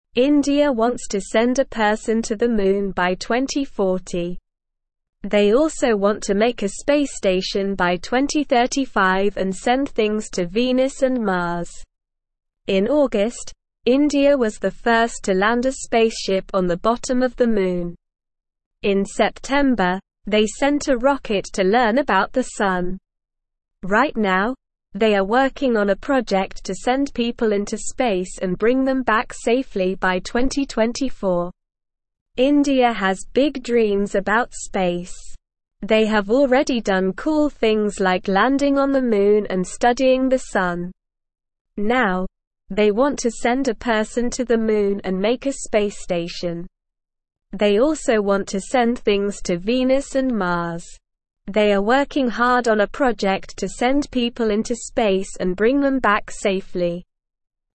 Slow
English-Newsroom-Beginner-SLOW-Reading-Indias-Big-Space-Dreams-Moon-Sun-and-More.mp3